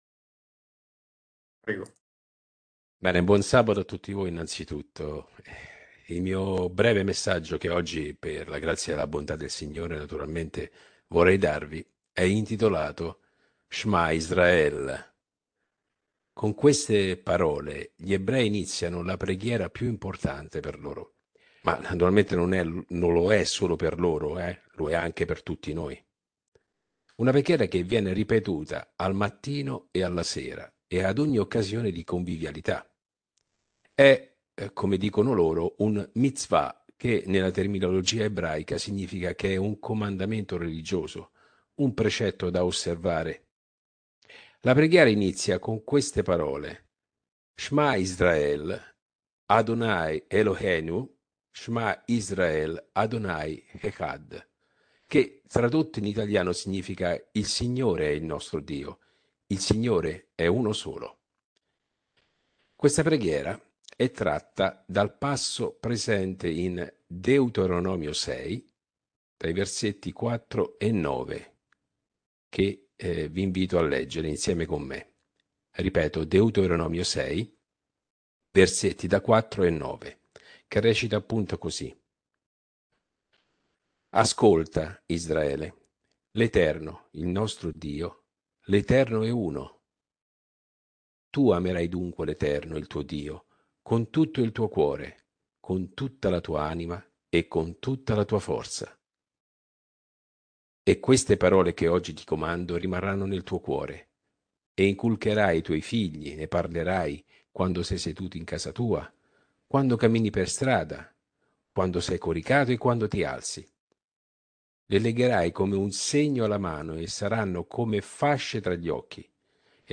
Sermonetto